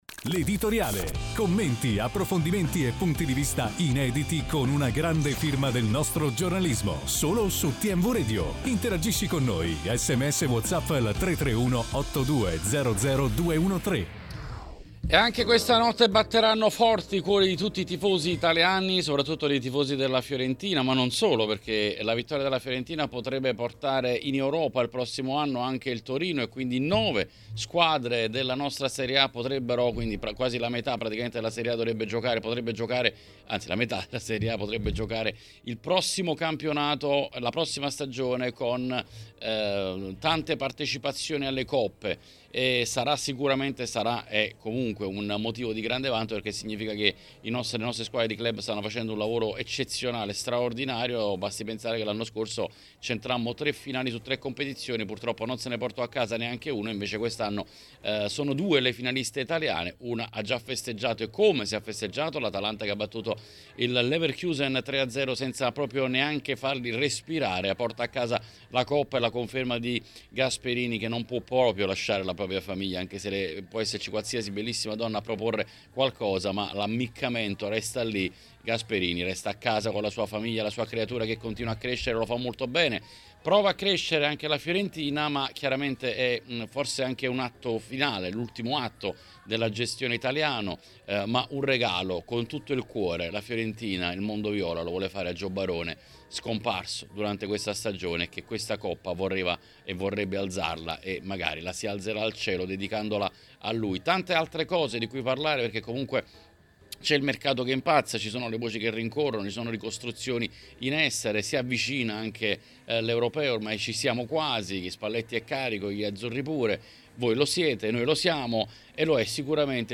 Durante l'appuntamento odierno con L’Editoriale sulle frequenze di TMW Radio è intervenuto Xavier Jacobelli.